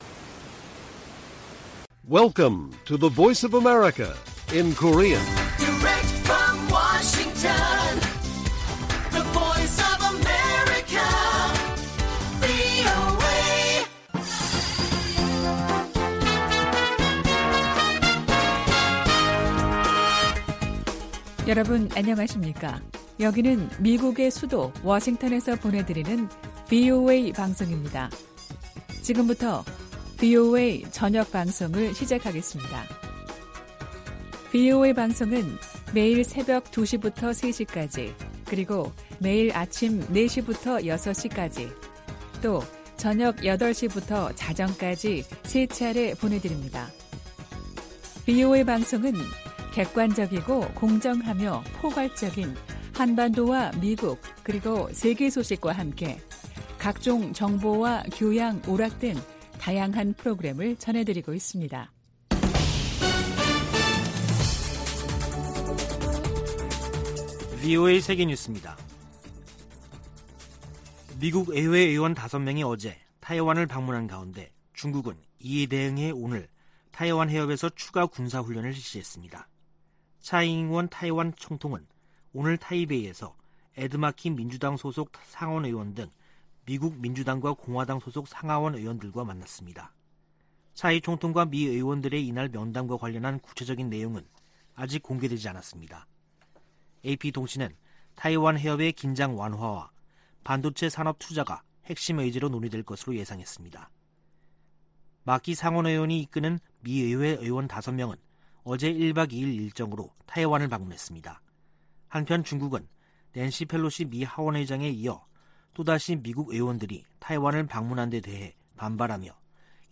VOA 한국어 간판 뉴스 프로그램 '뉴스 투데이', 2022년 8월 15일 1부 방송입니다. 윤석열 한국 대통령이 광복절을 맞아, 북한이 실질적 비핵화로 전환하면 경제를 획기적으로 개선시켜주겠다고 제안했습니다. 중국의 ‘3불 1한’ 요구에 대해 해리 해리스 전 주한 미국대사는 중국이 주권국가에 명령할 권리가 없다고 지적했습니다. 중국이 낸시 펠로시 미국 하원의장의 타이완 방문을 구실로 도발적인 과잉 반응을 지속하고 있다고 백악관 고위관리가 규탄했습니다.